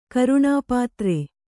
♪ karuṇāpātre